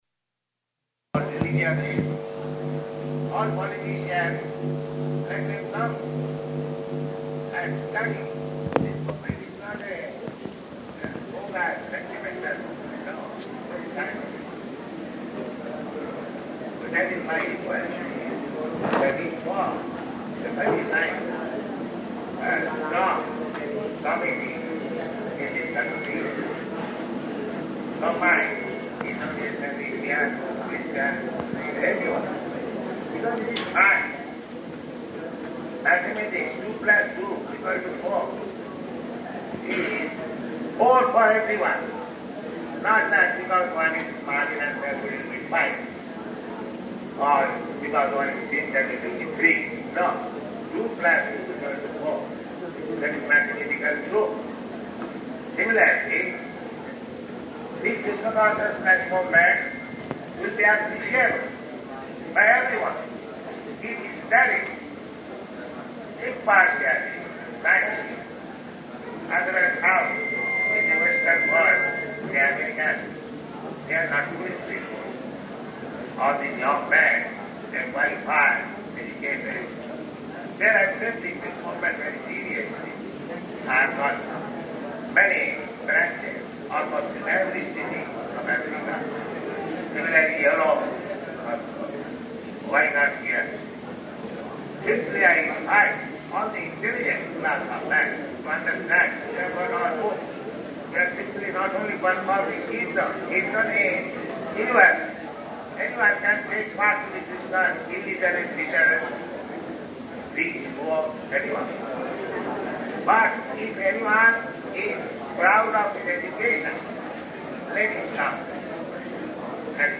Lecture [partially recorded]
Location: Jakarta
[Poor Recording]